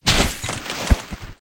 PixelPerfectionCE/assets/minecraft/sounds/mob/horse/armor.ogg at mc116
armor.ogg